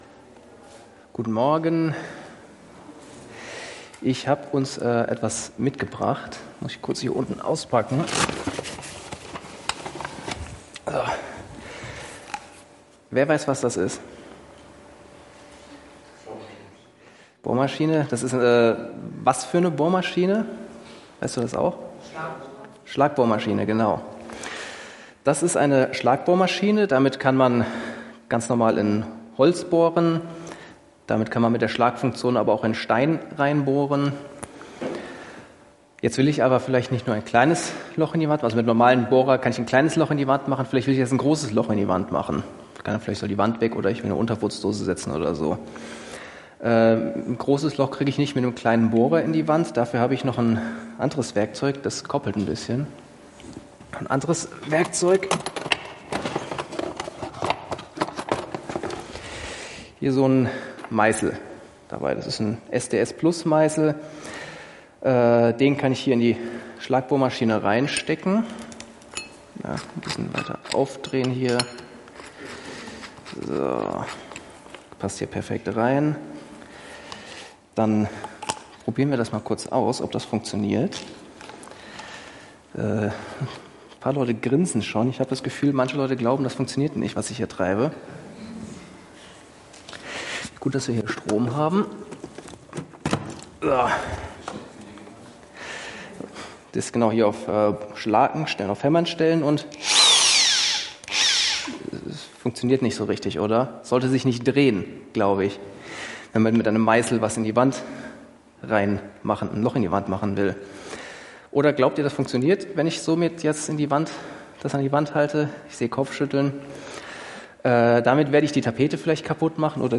Tue den Willen Gottes | Christus Gemeinde Coesfeld